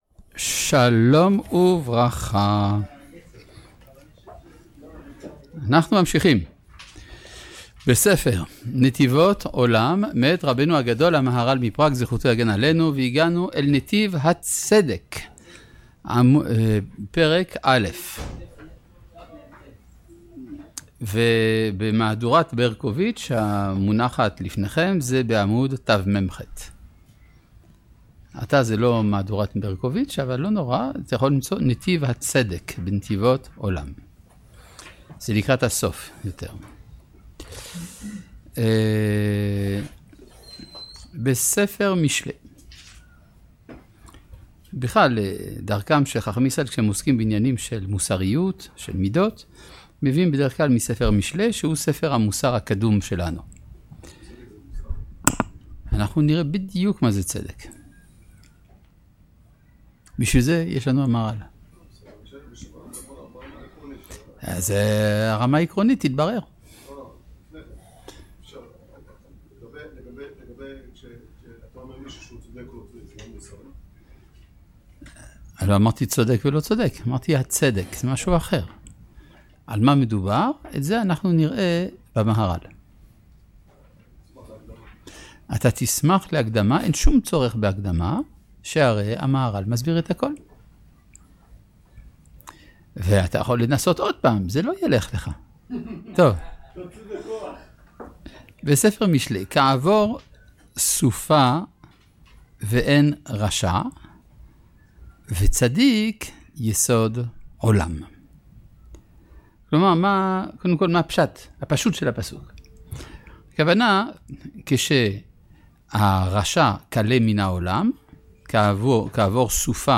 לימוד ספר נתיבות עולם למהר"ל מפראג